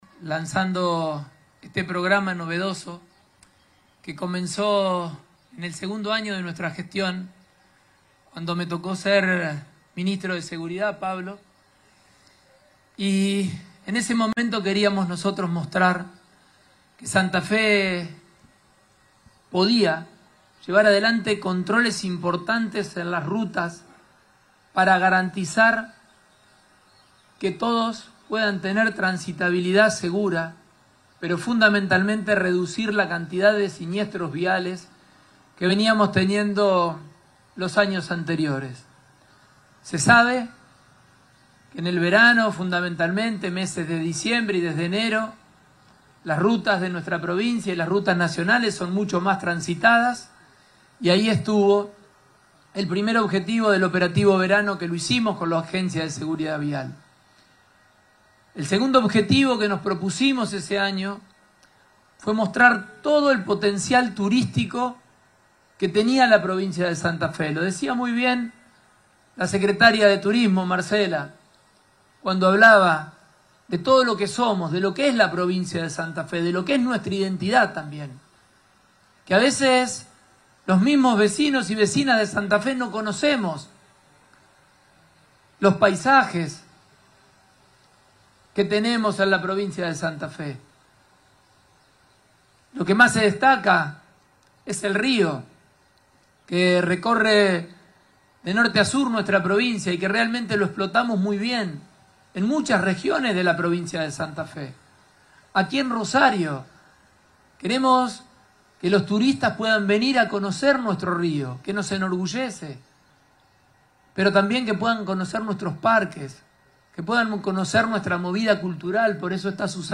Fue este viernes, en el balneario La Florida, en Rosario.
Declaraciones Pullaro